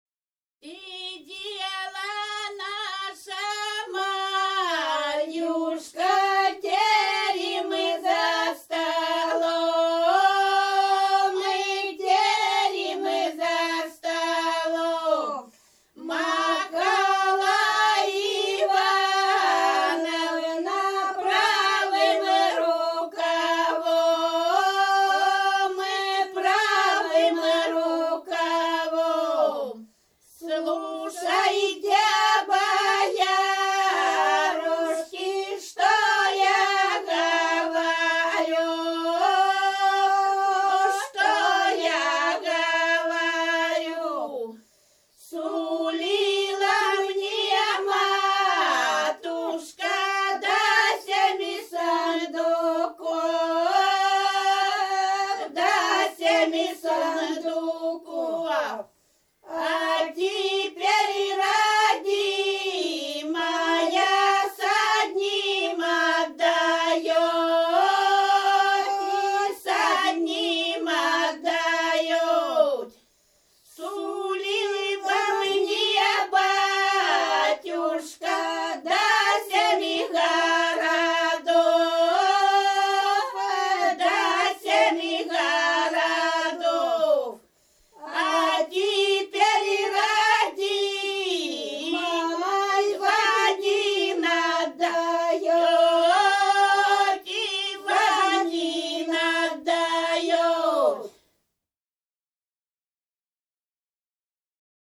Рязань Кутуково «Сидела наша Марьюшка», свадебная.